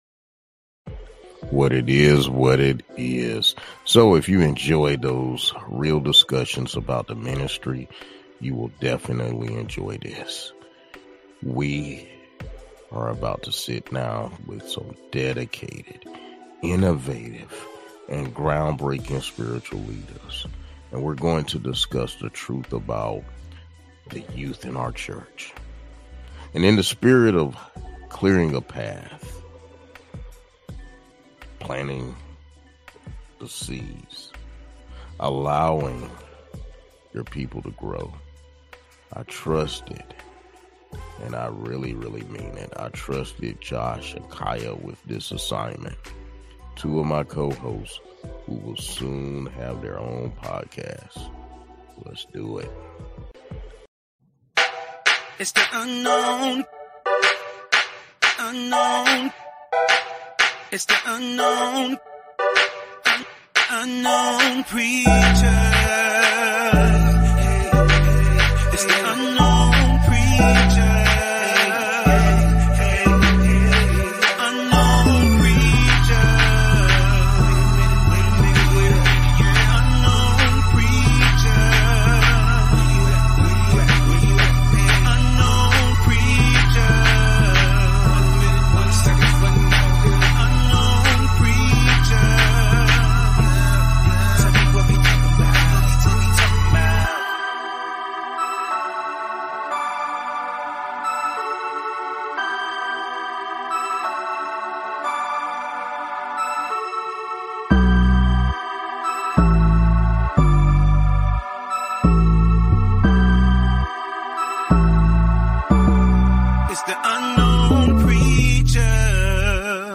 Join us, as we have a panel discussion on youth in the church.